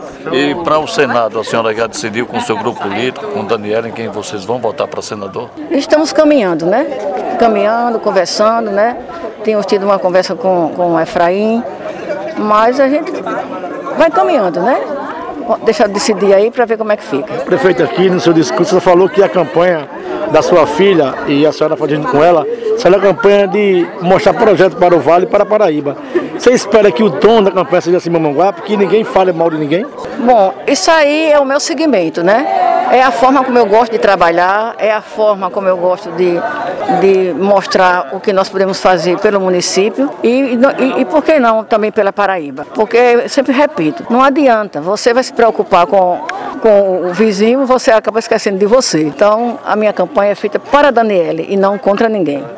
Prefeita de Mamanguape, Eunice Pessoa.
Eunice-Pessoa.wav